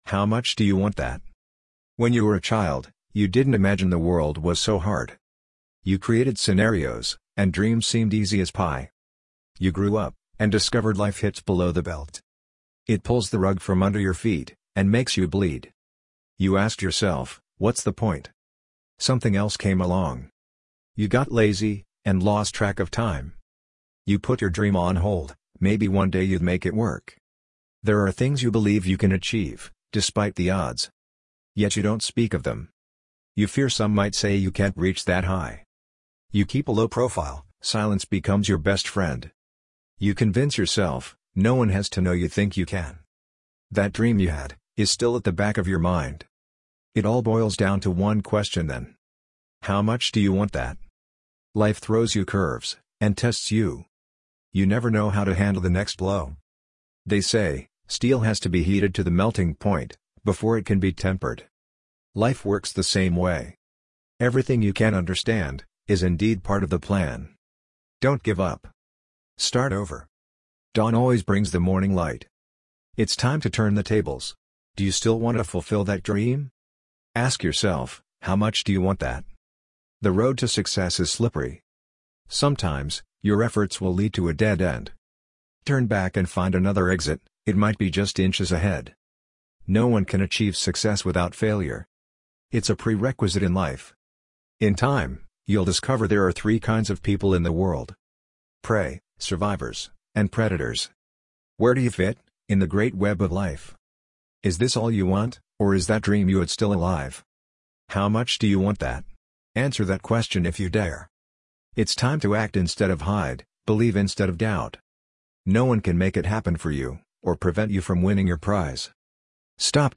voice-over-poel-how-much-do-you-want-that-2-3.mp3